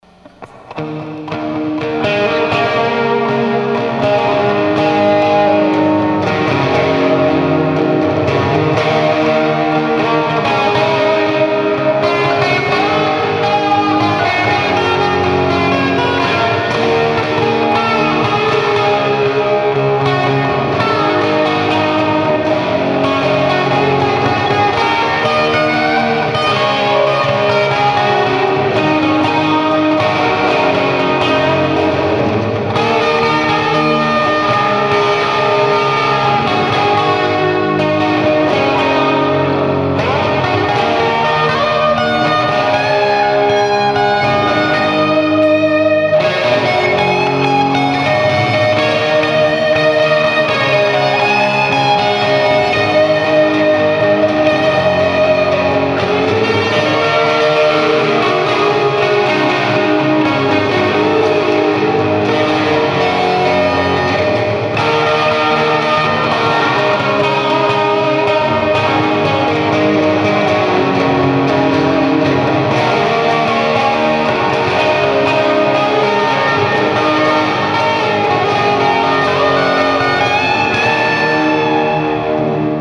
TONE SAMPLES - All clips plugged straight into amp unless noted.
Less than one thousandth of a watt, barely audible without mic.